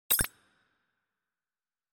جلوه های صوتی
دانلود صدای آب 58 از ساعد نیوز با لینک مستقیم و کیفیت بالا
برچسب: دانلود آهنگ های افکت صوتی طبیعت و محیط دانلود آلبوم صدای آب از افکت صوتی طبیعت و محیط